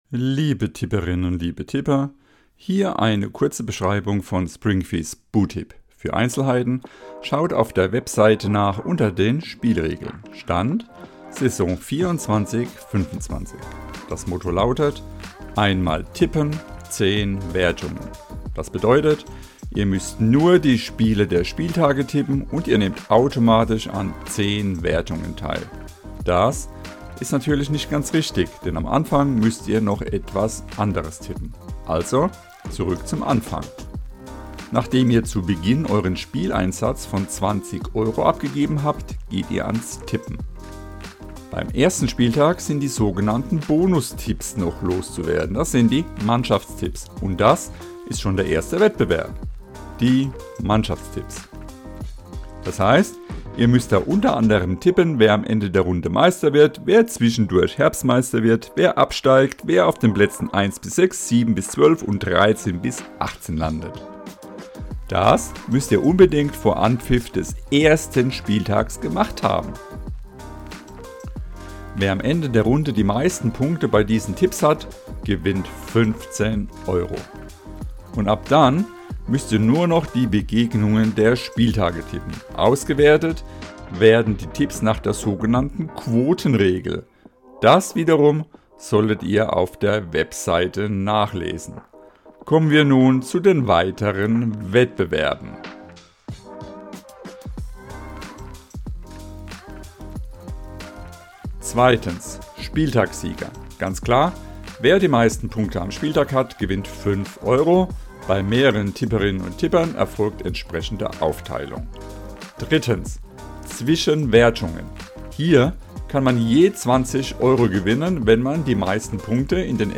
Hintergrundmusik:
jazzy-abstract-beat-11254